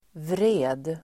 Uttal: [vre:d]